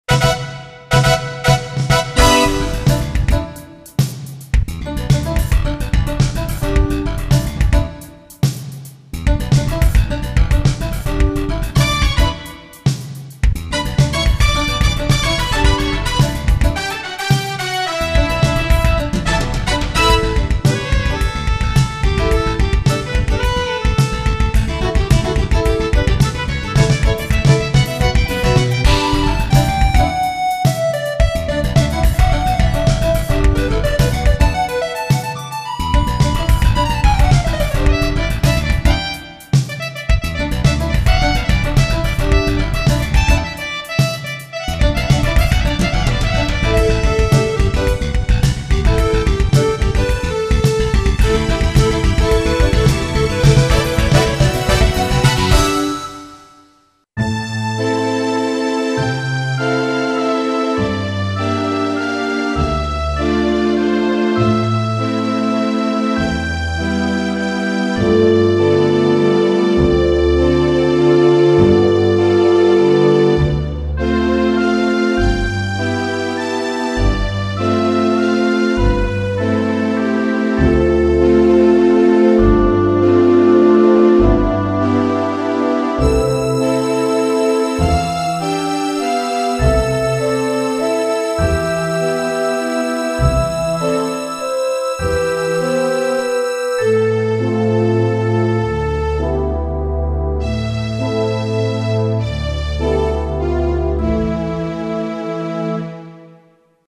Yamaha Y-SXG50 Software Synthesizer